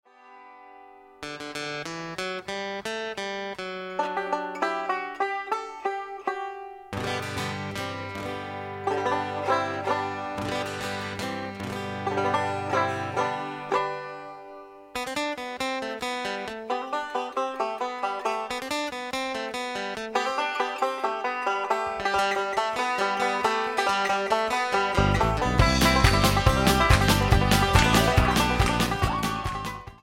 Genre / Stil: Country & Folk